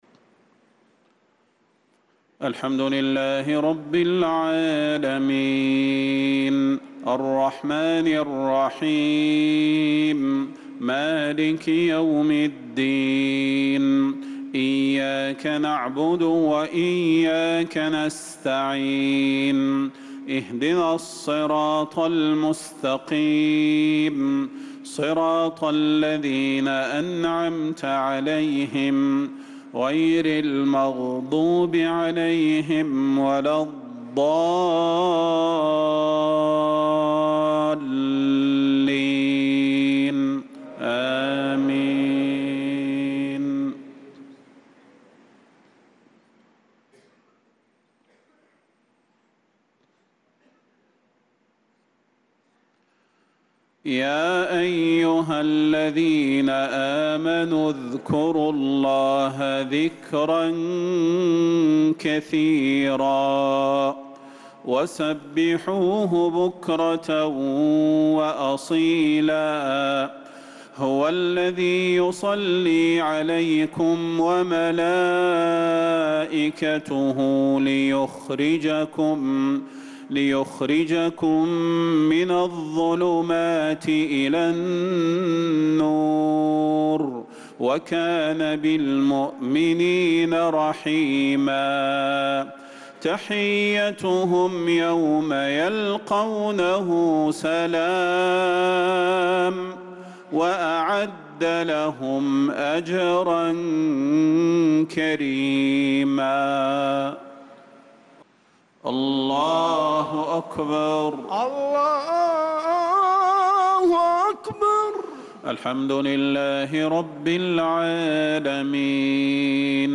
صلاة العشاء للقارئ صلاح البدير 30 ذو الحجة 1445 هـ
تِلَاوَات الْحَرَمَيْن .